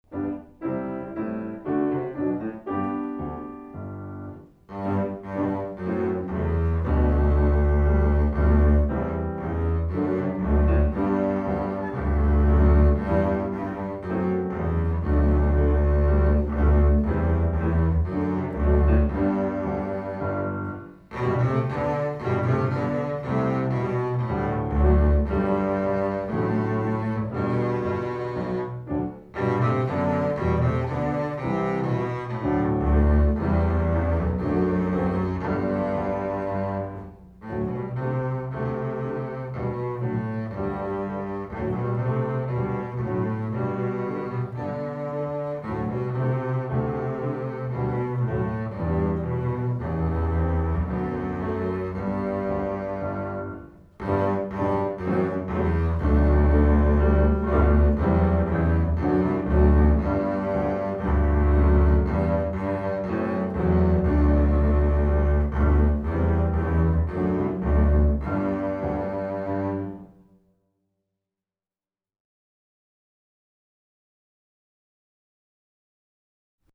Voicing: String Bass Method w/ Audio